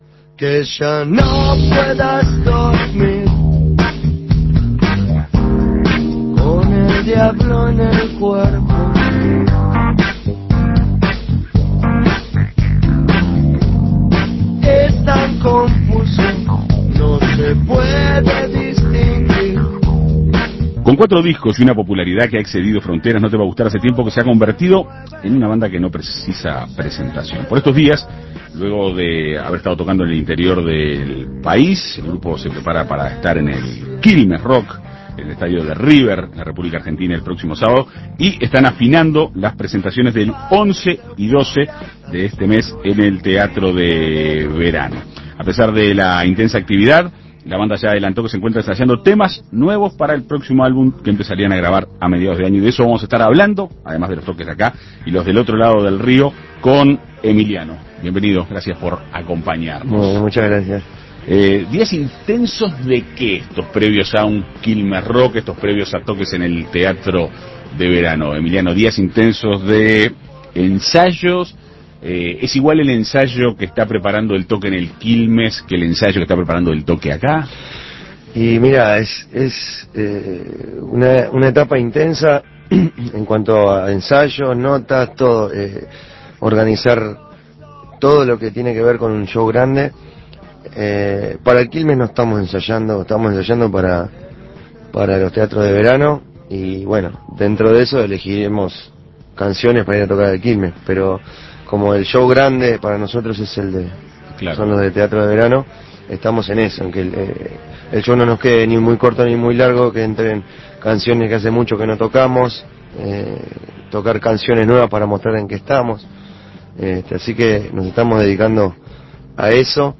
No te va gustar (NTVG) prepara los recitales del 11 y 12 de abril en el Teatro de Verano y además su participación en el Quilmes Rock en el Estadio Monumental de River Plate, en Argentina. En Perspectiva Segunda Mañana dialogó con Emiliano Brancciari, vocalista de la banda.